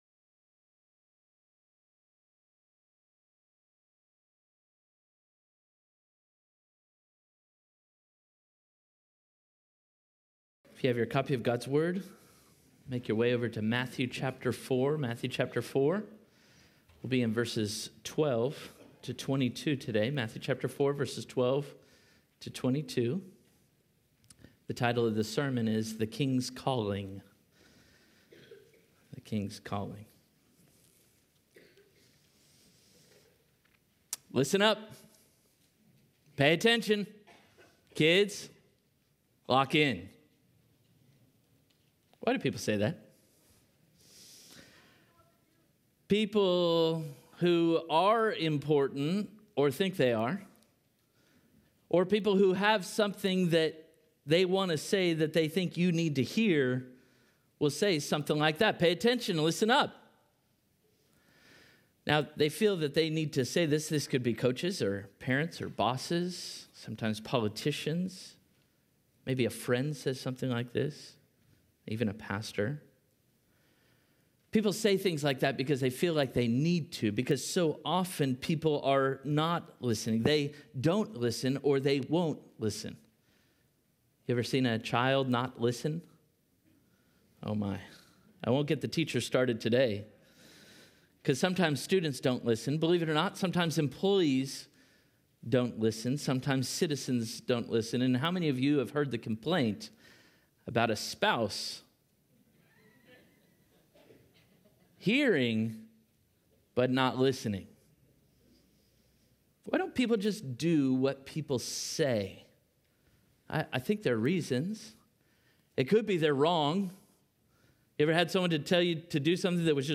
Parkway Sermons